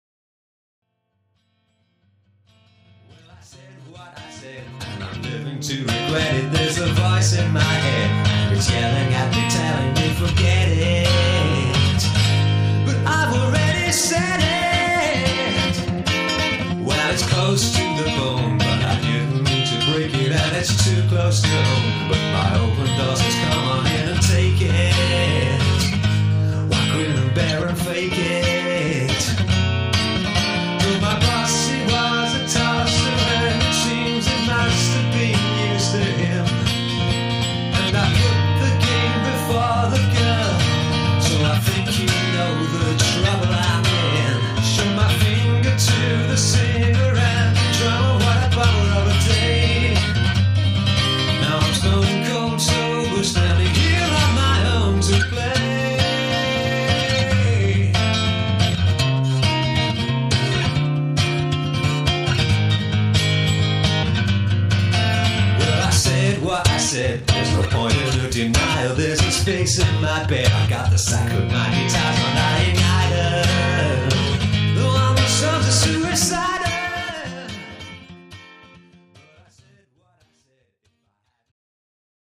acoustic album